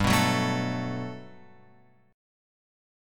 G Major 9th